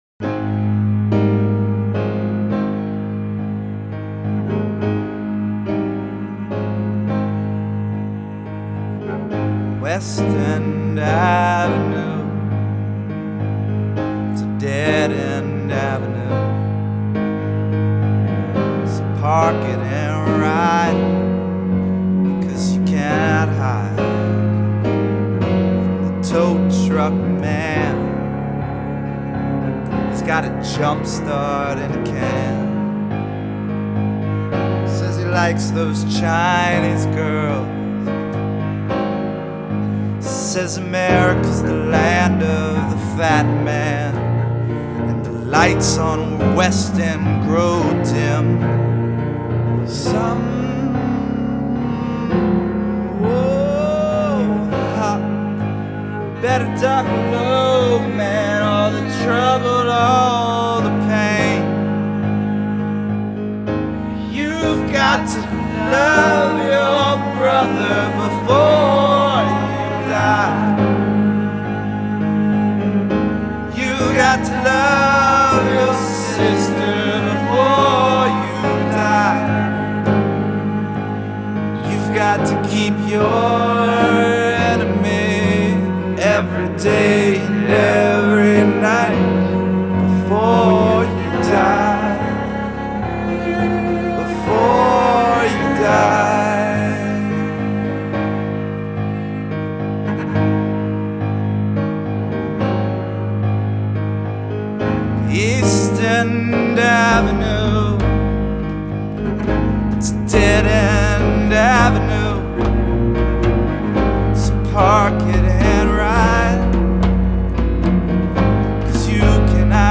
CD-R cellos sample track